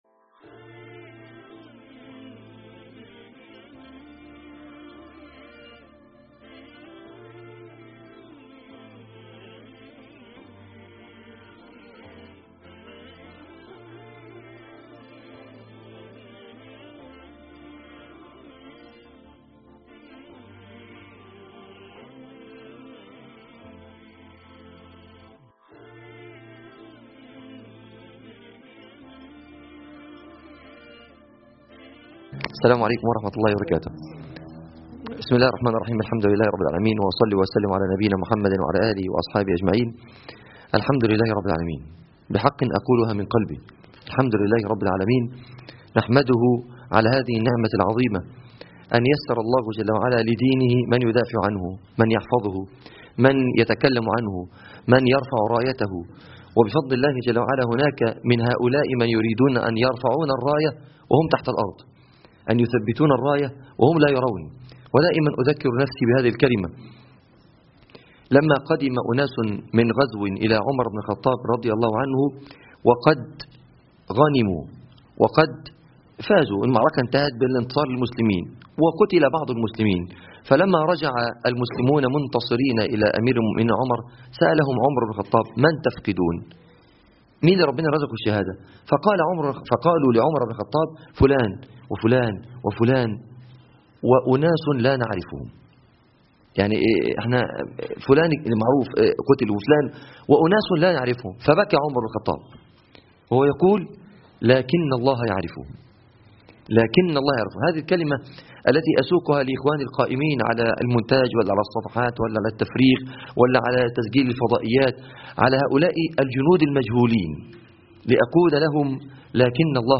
التغطيات الاعلامية برعاية شبكة الطريق الى الله     حفل تكريم فرق عمل شبكة الطريق إلى الله